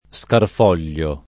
Scarfoglio [ S karf 0 l’l’o ] cogn.